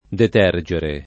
vai all'elenco alfabetico delle voci ingrandisci il carattere 100% rimpicciolisci il carattere stampa invia tramite posta elettronica codividi su Facebook detergere [ det $ r J ere ] v.; detergo [ det $ r g o ], -gi — coniug. come tergere